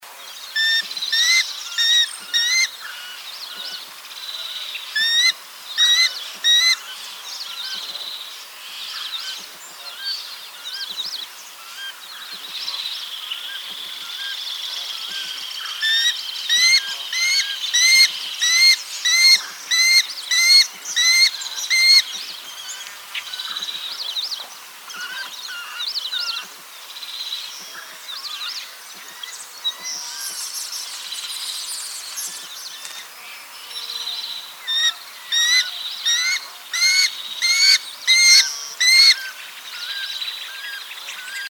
Spiziapteryx circumcinctus - Halconcito gris
halconcitogris.wav